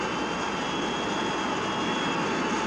engine_2.wav